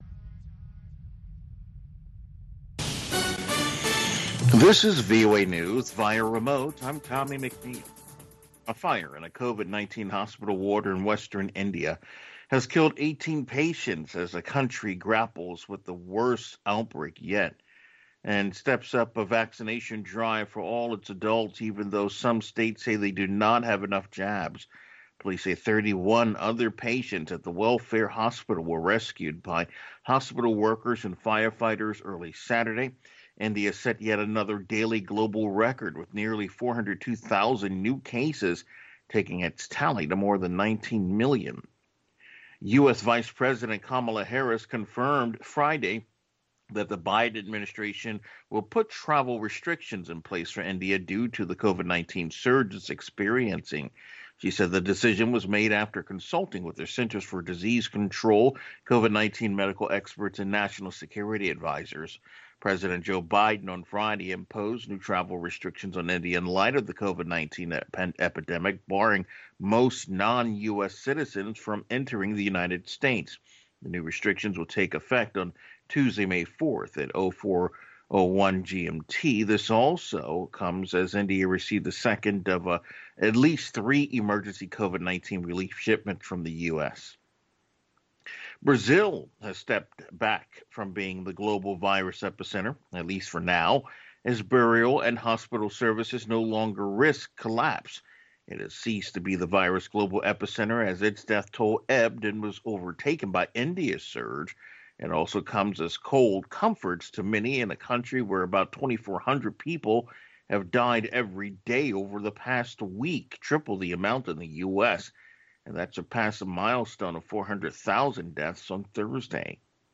rare recordings